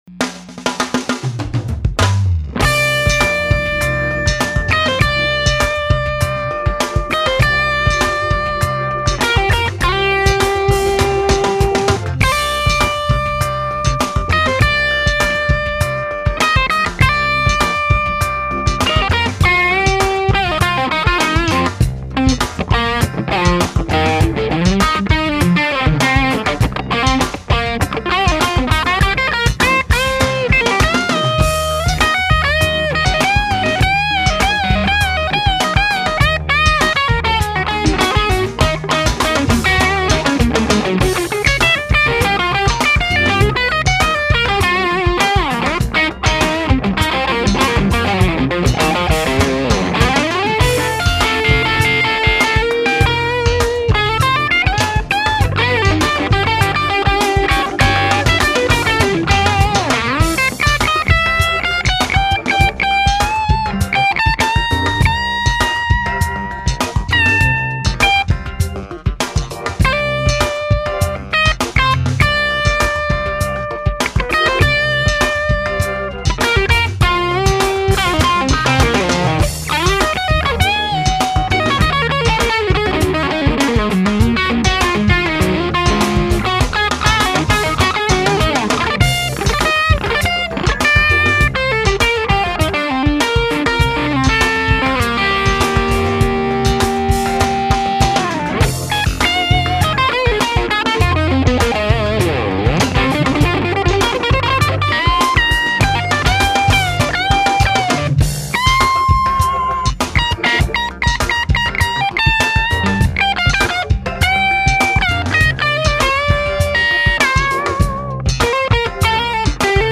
Here's another 15 clip
The second clip was with the 135 with p90's. You can hear the large midrange content of the part.
Trinity_15_Funk_135.mp3